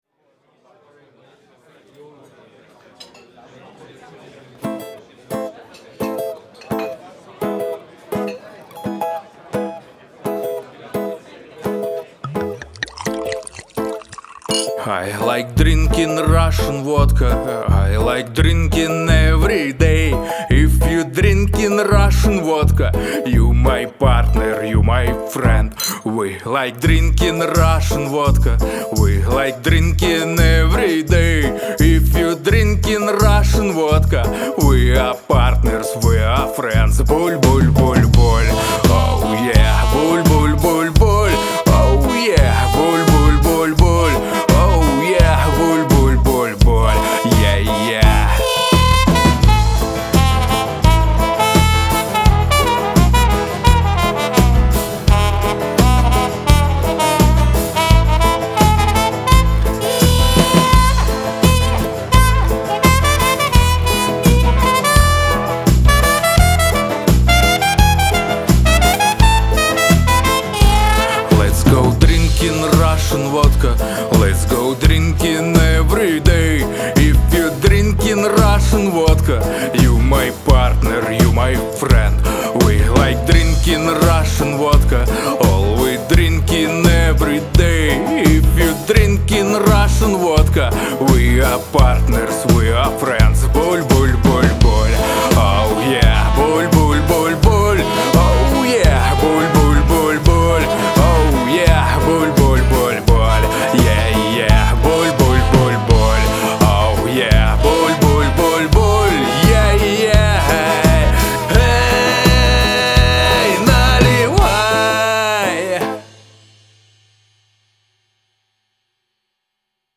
С ярковыраженным русским акцентом я делал одну песенку, специально.